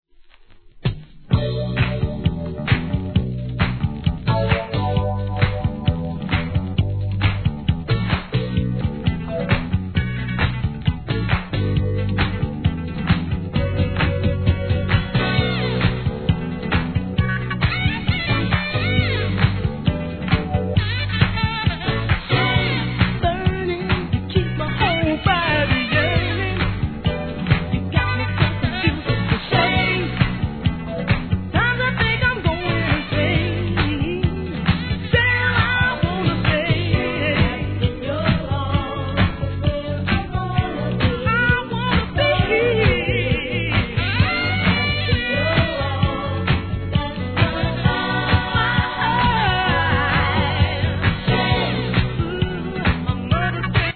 ¥ 330 税込 関連カテゴリ SOUL/FUNK/etc...